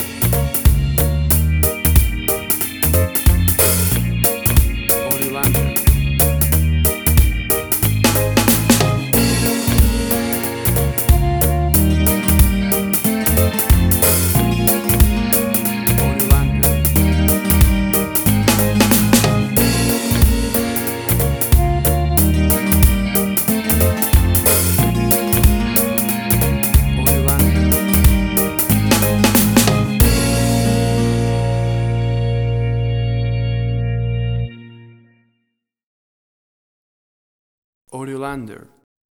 WAV Sample Rate: 16-Bit stereo, 44.1 kHz
Tempo (BPM): 92